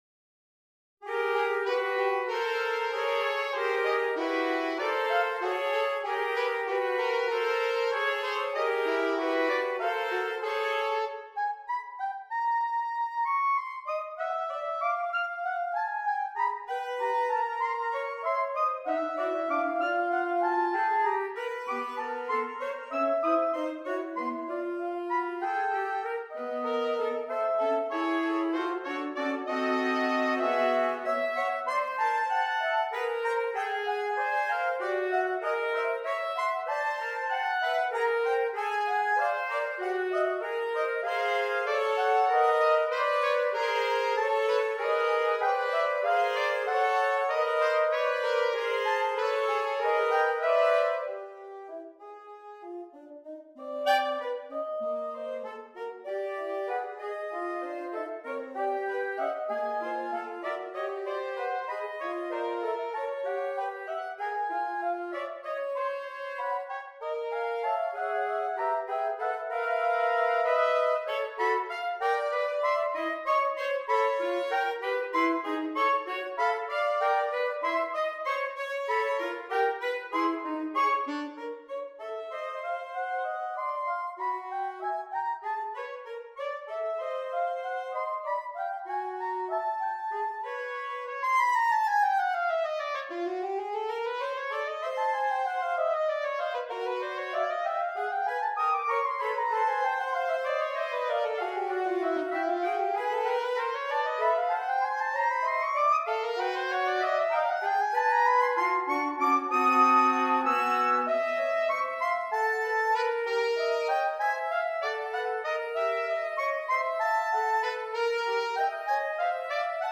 Saxophone Quartet (SATB)